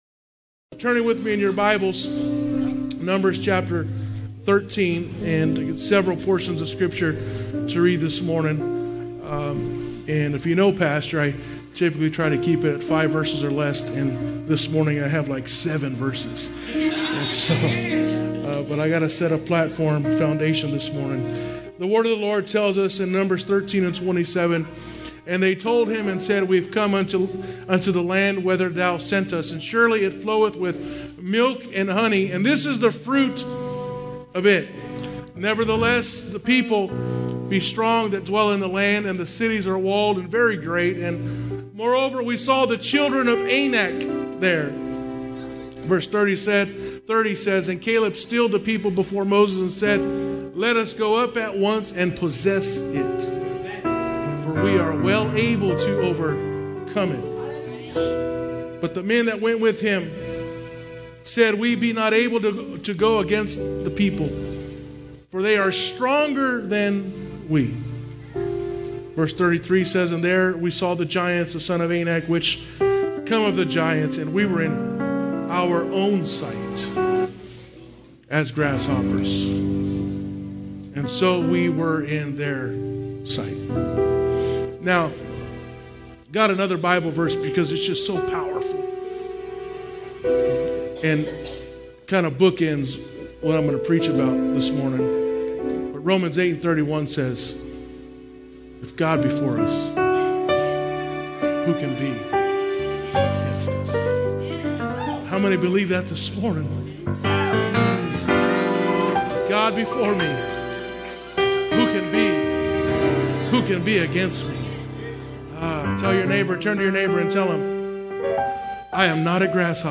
Ministry Staff Service Type: Sunday Morning Worship Passage: Numbers 13:27-28, Numbers 13:30-31, Numbers 13:33, Romans 8:31